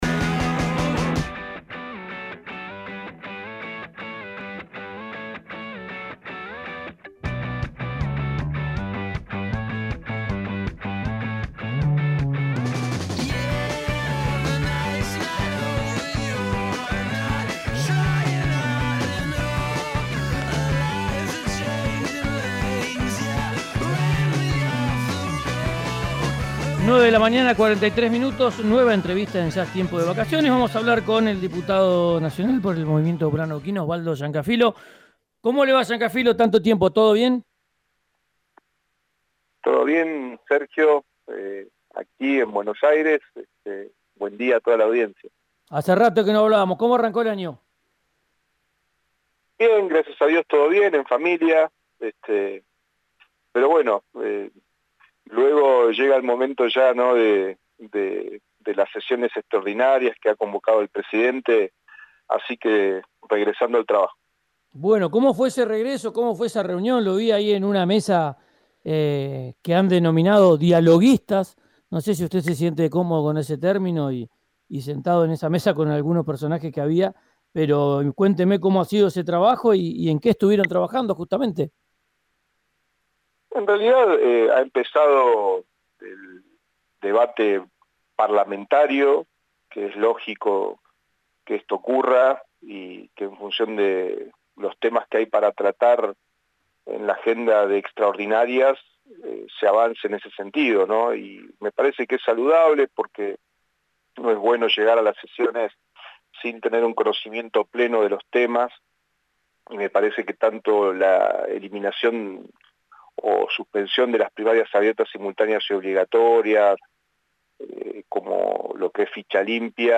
Escuchá a Osvaldo Llancafilo en RÍO NEGRO RADIO: